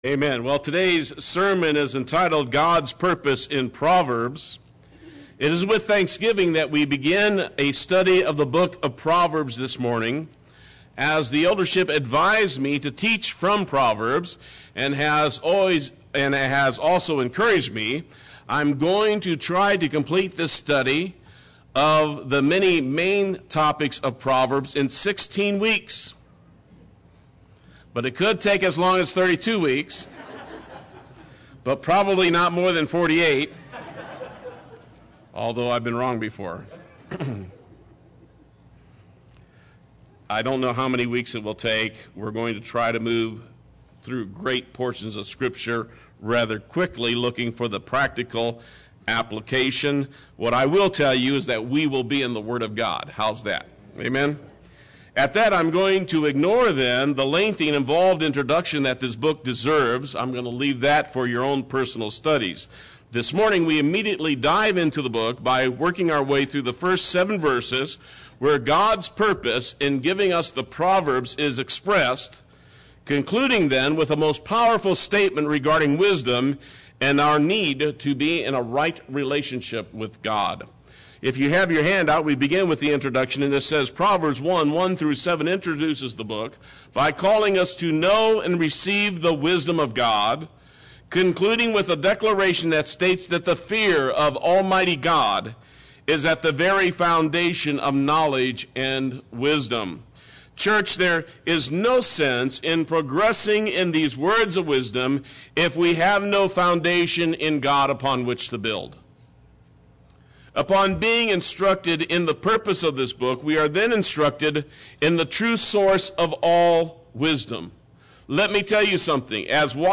Play Sermon Get HCF Teaching Automatically.
God’s Purpose in Proverbs Sunday Worship